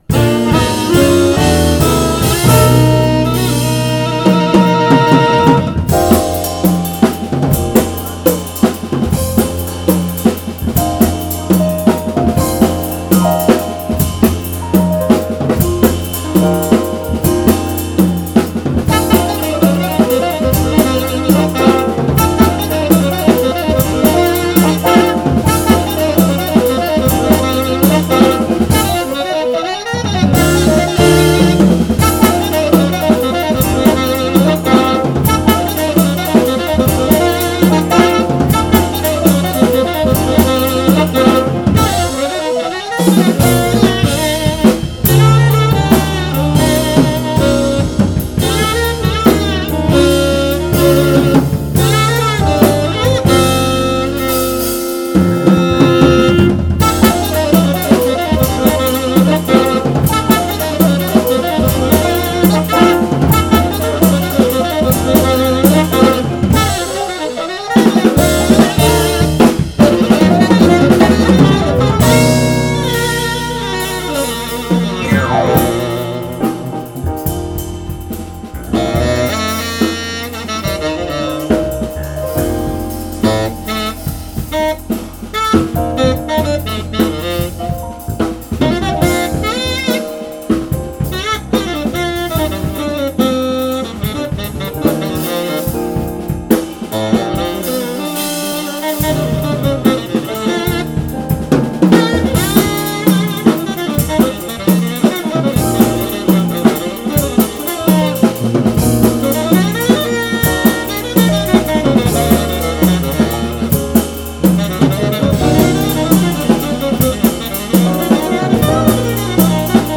Jazz
trumpet, fluegelhorn, and FX
tenor sax
electric bass